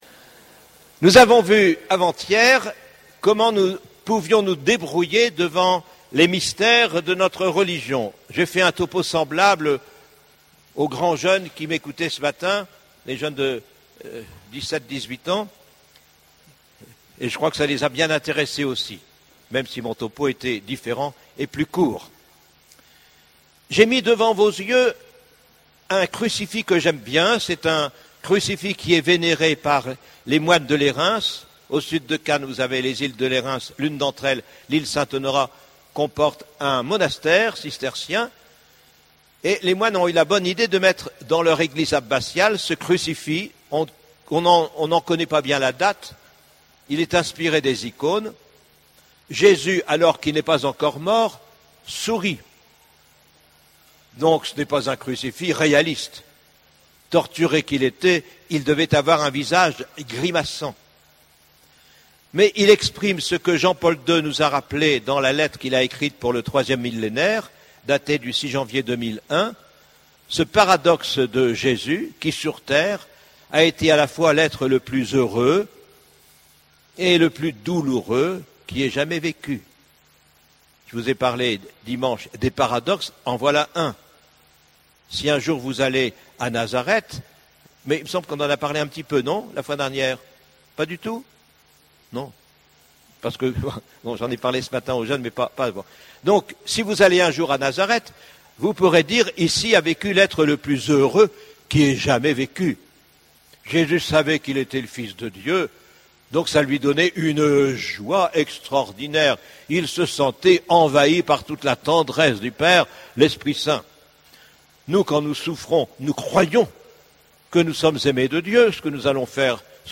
Session Familles et Tous : R�pandre l'amour qui nous fait vivre (Beno�t XVI) Paray-le-Monial du 1 au 6 ao�t 2009) Parcours Vivre une foi plus sereine Enregistr� le 4 ao�t 2009.
MP3 64Kbps Mono Taille